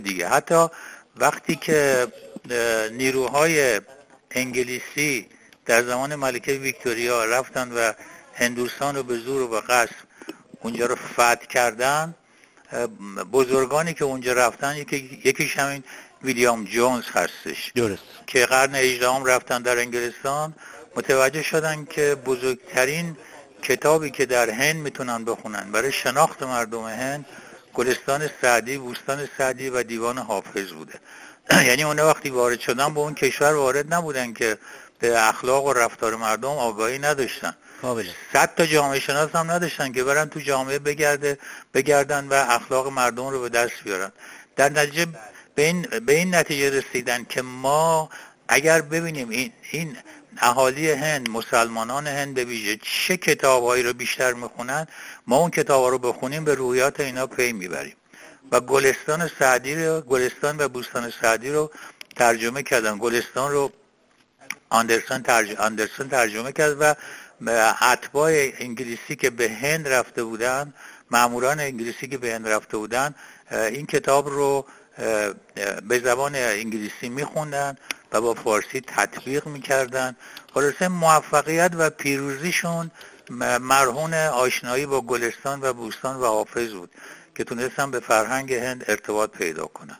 روزگار و زمانه سعدی در گفت‌وگوی ایکنا